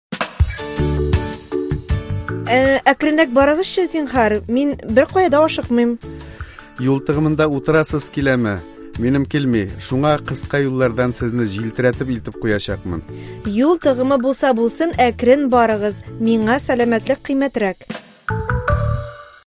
Представляем вашему вниманию три диалога с таксистом.
Диалог 1